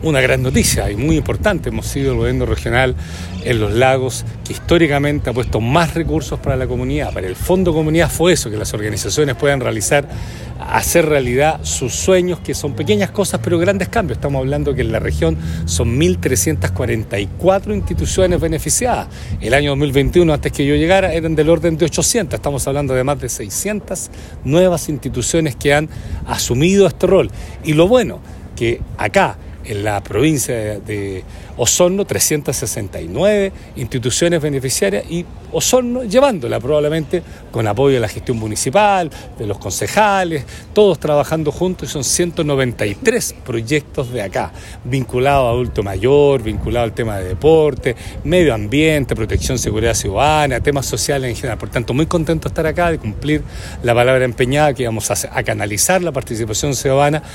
Ayer jueves, en la Casa del Folclor de Osorno, se llevó a cabo la Ceremonia de Firma de Convenio del Fondo Comunidad, donde 193 organizaciones territoriales y funcionales de la comuna recibieron recursos destinados a la ejecución de proyectos en diversas áreas, como Seguridad Ciudadana, Adultos Mayores, Fondo Social, entre otras.
En esta oportunidad, se destinaron cerca de $1.300 millones en la Provincia de Osorno, según detalló el Gobernador Regional Patricio Vallespin.